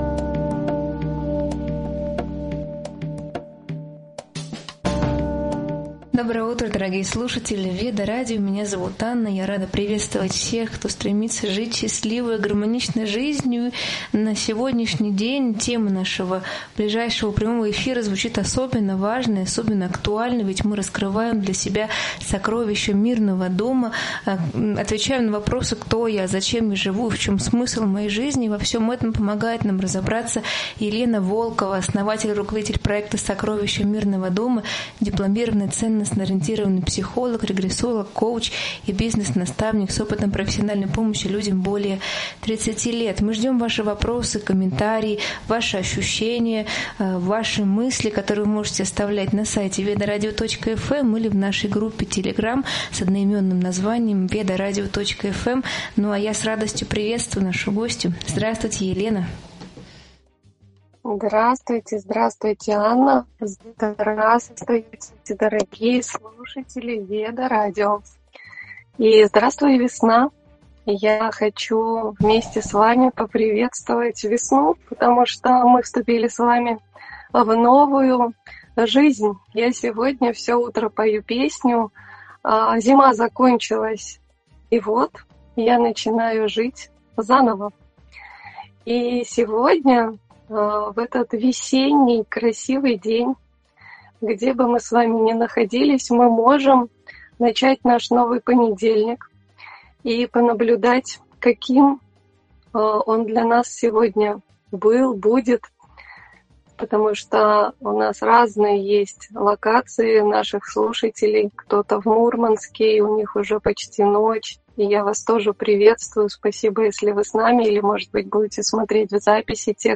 Доброе утро и приветствие слушателей